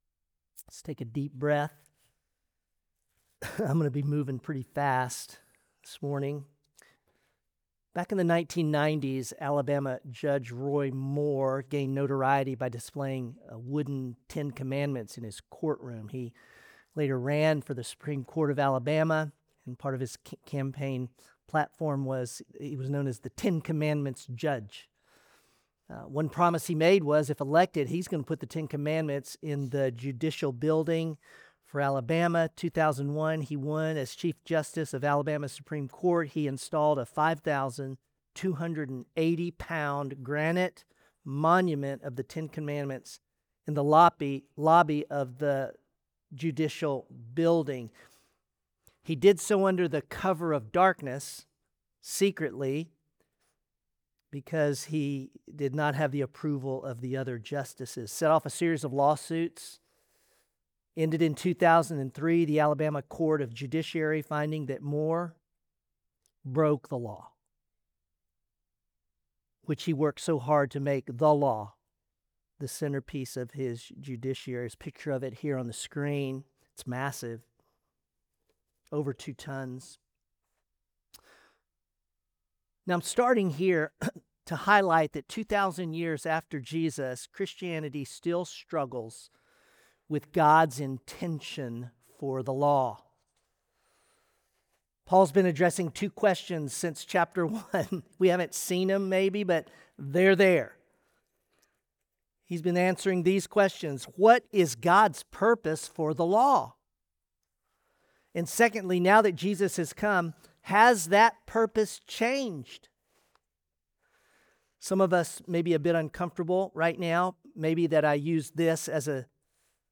Share This Sermon Romans: Righteousness Received